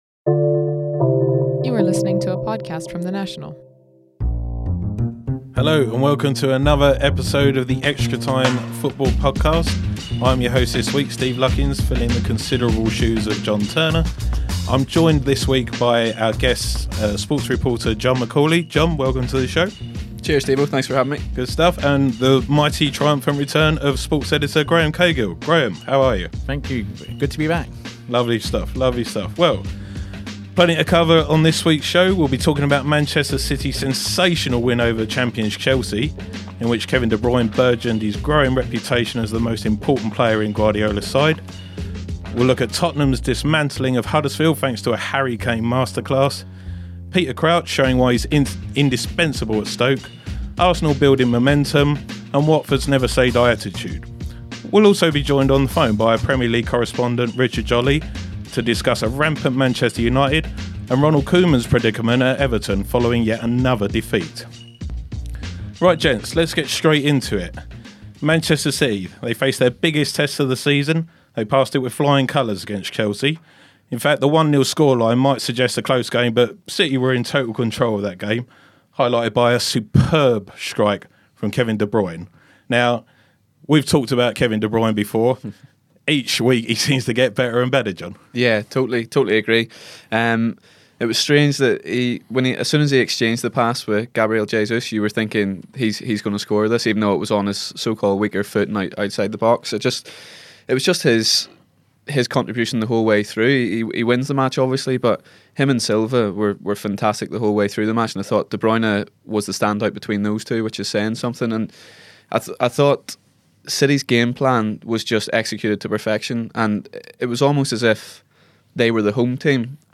on the phone